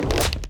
Rock Meteor Throw 2.ogg